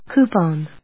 /kúːpɑn(米国英語), kjúːɑn(英国英語)/